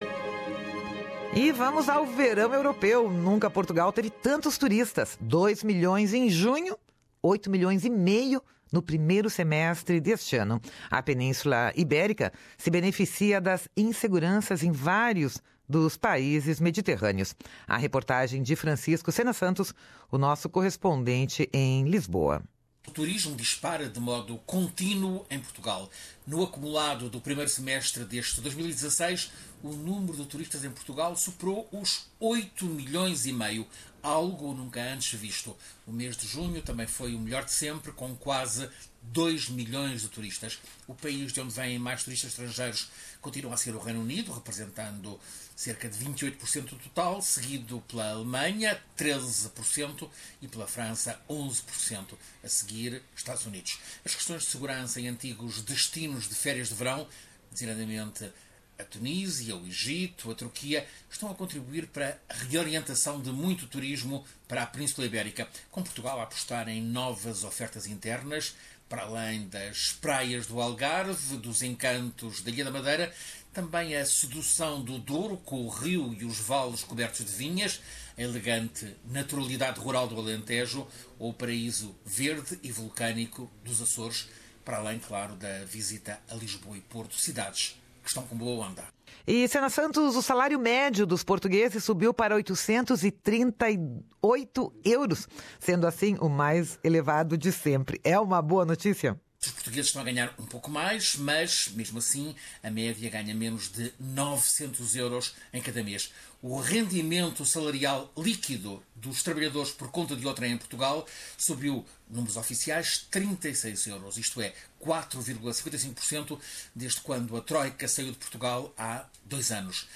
A Península Ibérica beneficia das inseguranças em vários dos países mediterrâneos. Reportagem